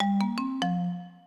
thunder2 acfa384f7f - Added default sounds (on first start and a new button in sound settings) ...